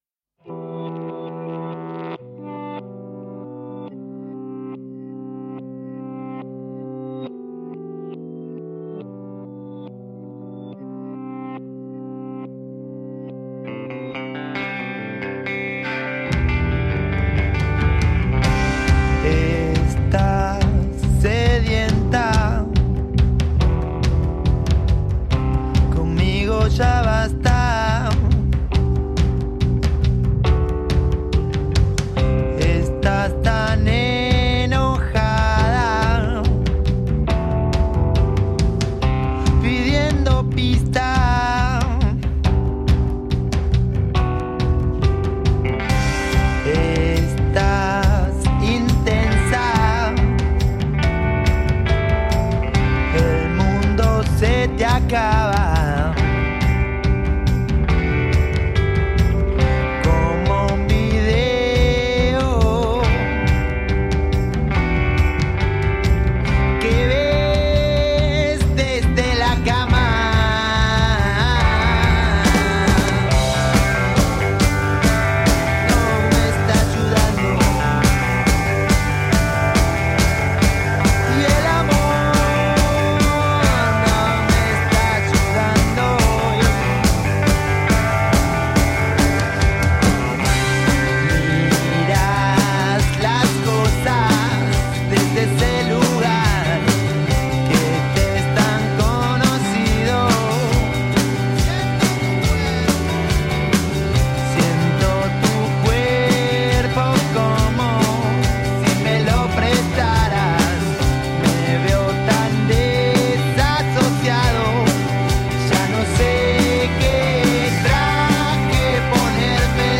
Funk jazz salsa rock from argentina.
Tagged as: Alt Rock, Latin